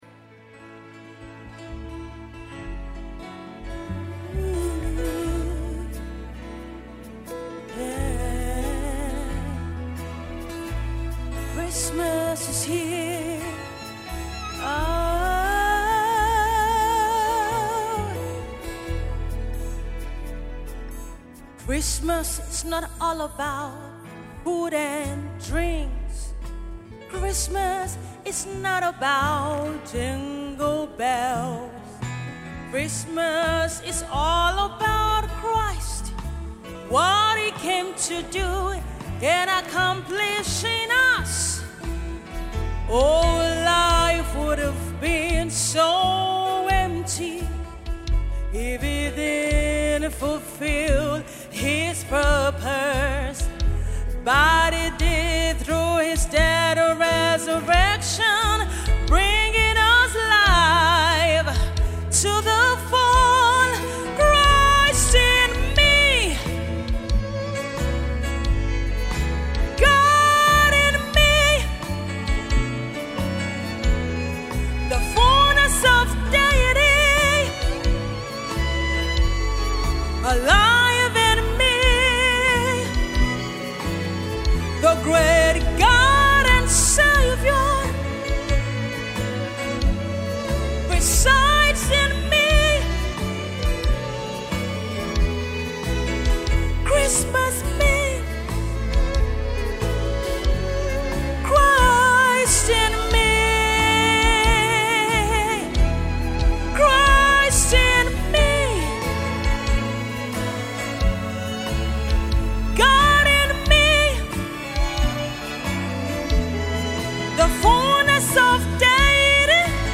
MORE CAROL SONGS